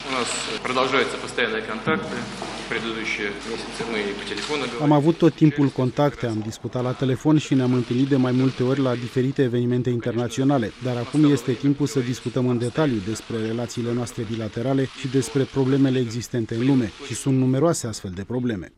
Înaintea întrevederii cu uşile închise, Donald Trump şi Vladimir Putin şi-au strâns mâinile şi apoi au făcut scurte declaraţii în faţa presei.
Vladimir-Putin.wav